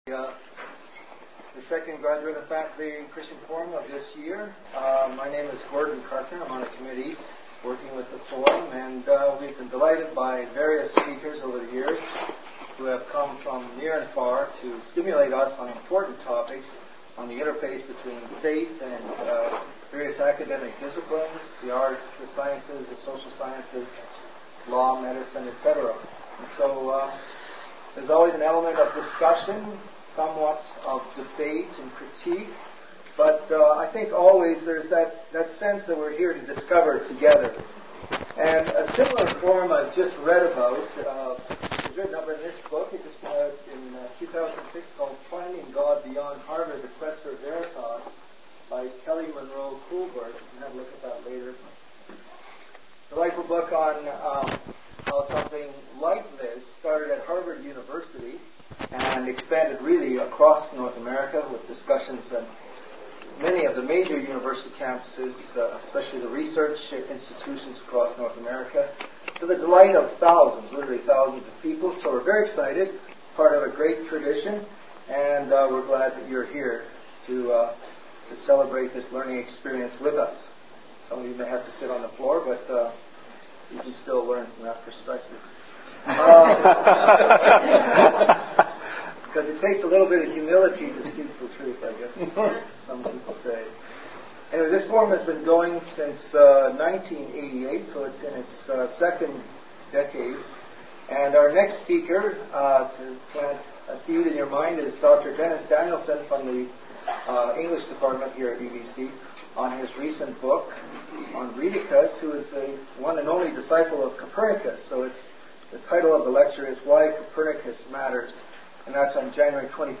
Insights from a Presentation by Joseph Stiglitz at UBC Law School